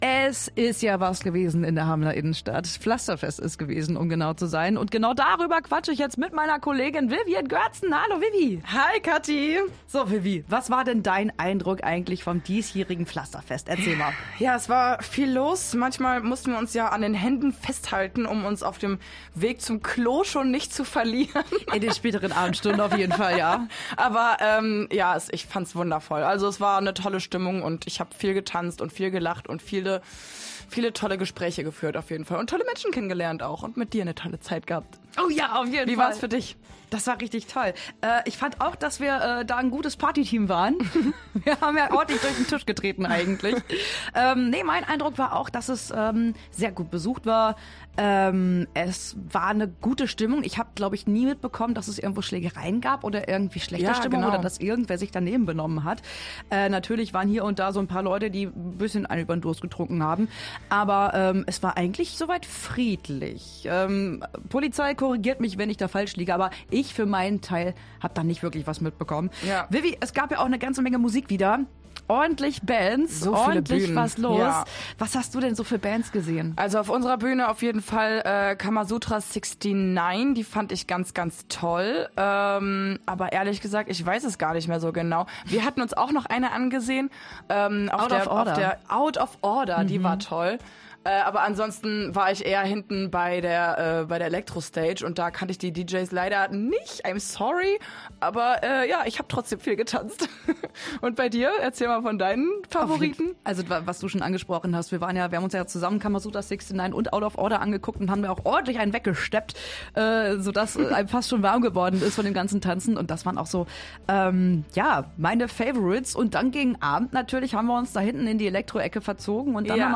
Hameln: radio aktiv Moderatorinnen teilen Eindrücke vom Pflasterfest